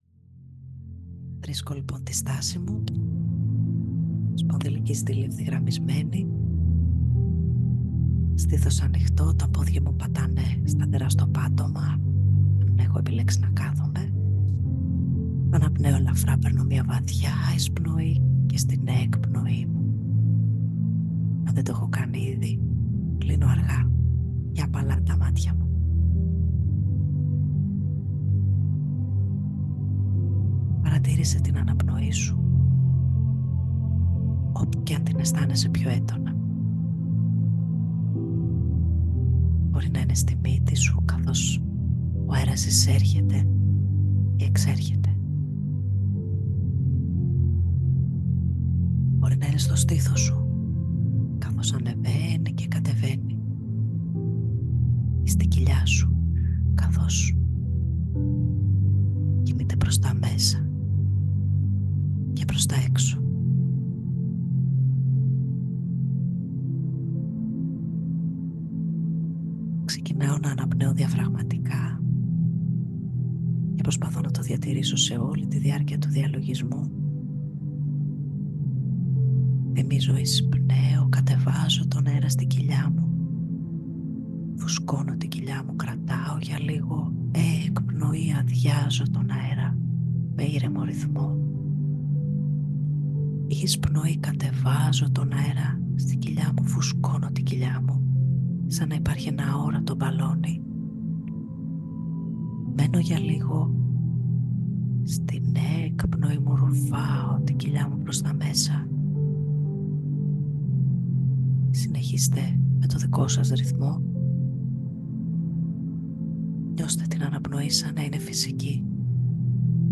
Διαλογισμός Επίγνωσης Αναπνοής (11λεπτά)